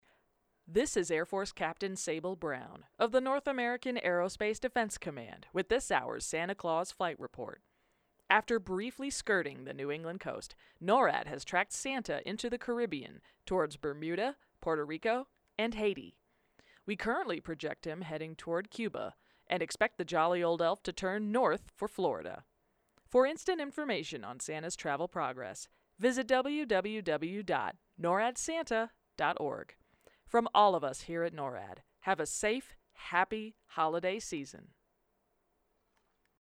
NORAD Tracks Santa radio update to be aired at 8pm MTS on December 24, 2022